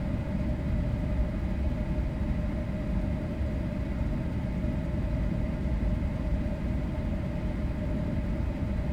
background_air_vent_fan_loop_01.wav